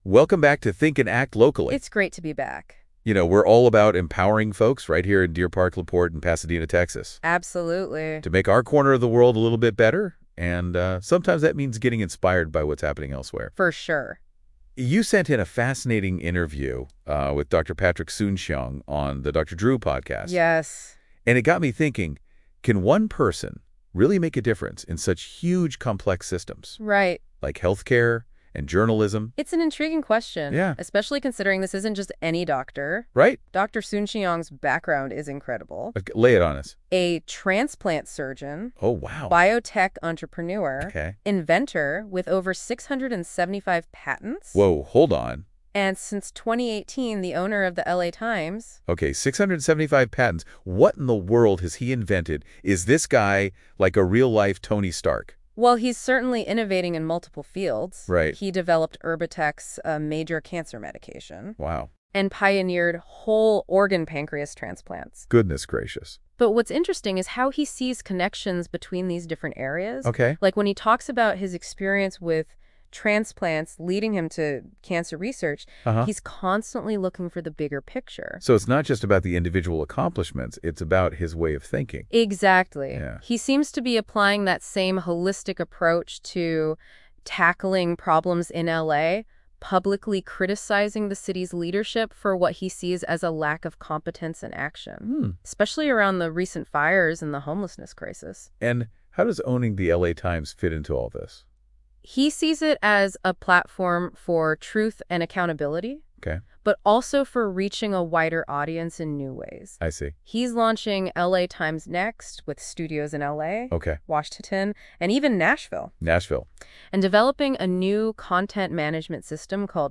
Dr. Patrick Soon-Shiong discusses cancer vaccines, healthcare innovation, and his vision for the LA Times in an insightful interview with Dr. Drew.